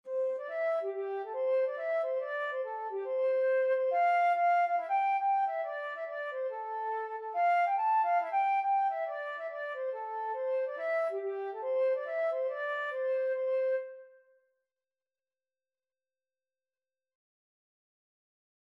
4/4 (View more 4/4 Music)
G5-A6
C major (Sounding Pitch) (View more C major Music for Flute )
Instrument:
Flute  (View more Easy Flute Music)
Traditional (View more Traditional Flute Music)